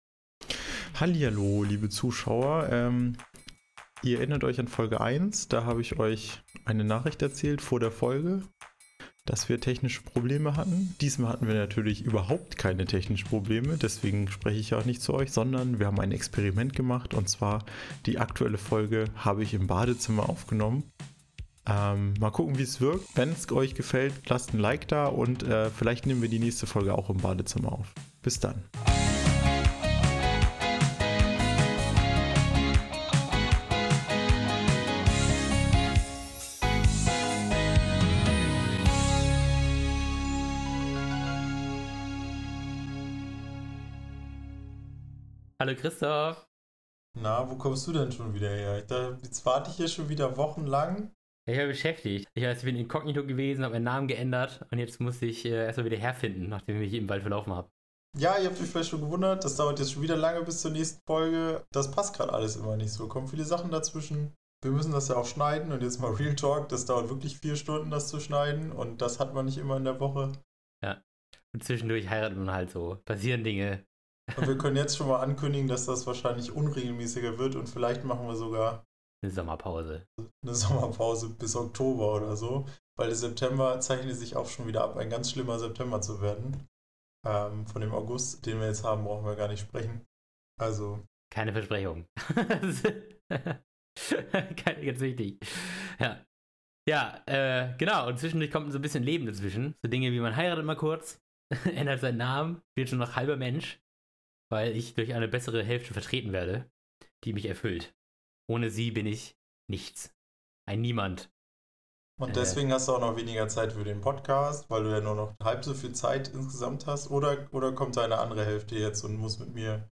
Magische Momente und hallende Stimmen in dieser Sonder-Episode.